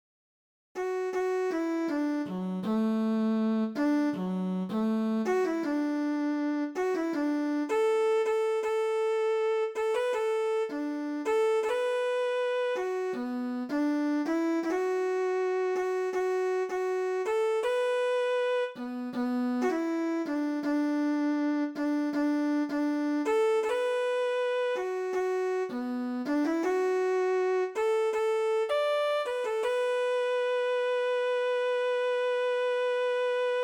THIẾU NHI CA